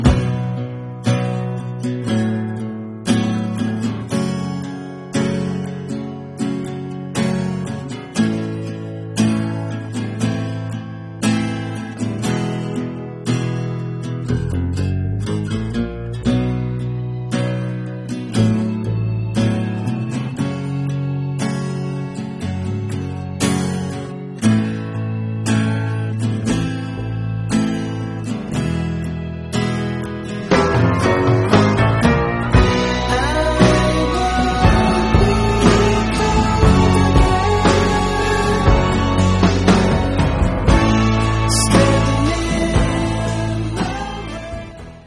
Blues
Rock